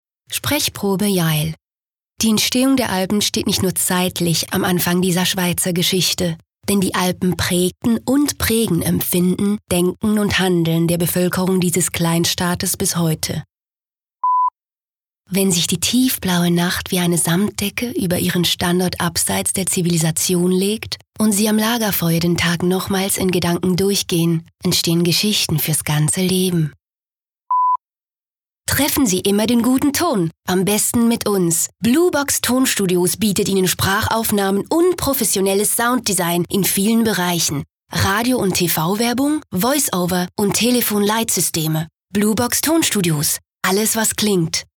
OFF-Kommentar Hochdeutsch (CH)
Sprecherin mit breitem Einsatzspektrum.